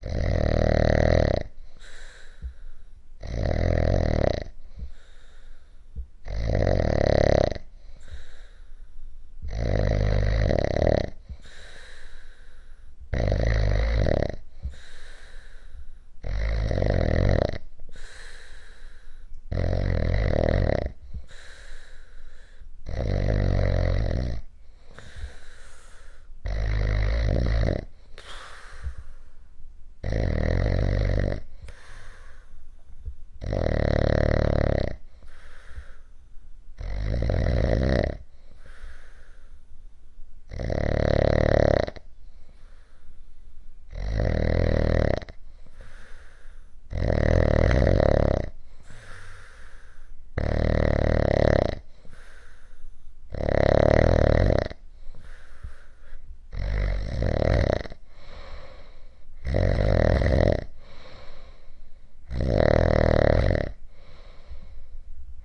东京 日本 " 列车长的日本火车广播
描述：我们在日本旅行时记录了这个声音。
Tag: 来讲 日本 电台 火车站 机场 日本东京 导体 宣布 公告